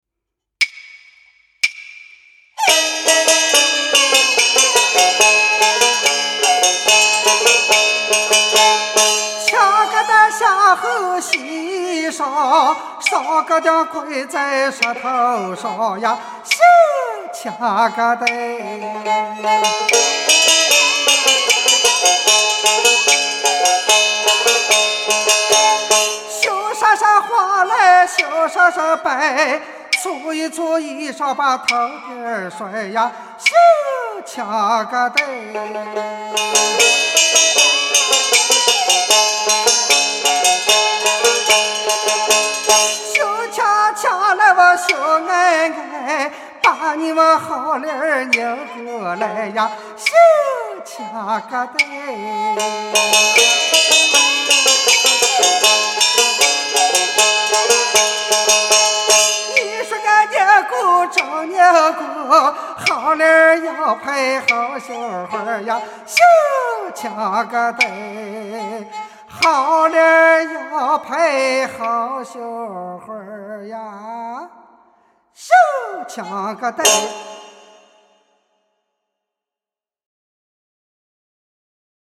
山西左权民歌